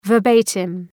Προφορά
{vər’beıtım}